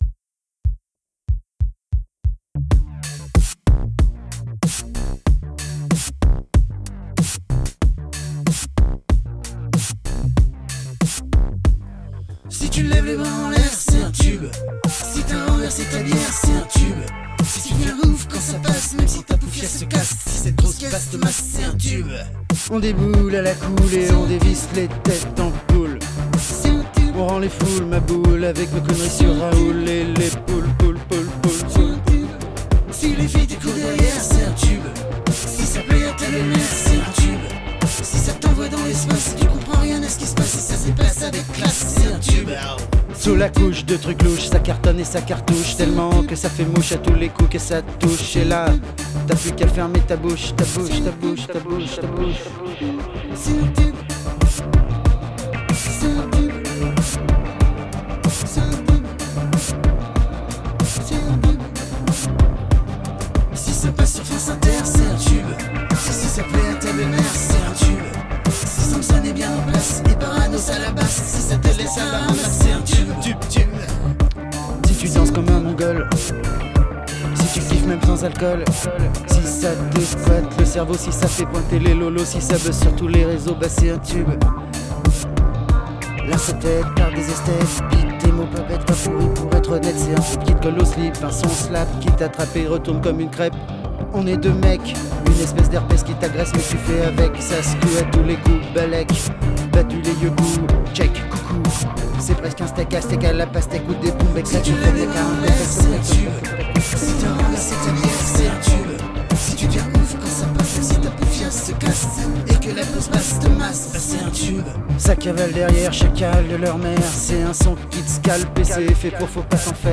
avec un mix pas au top ok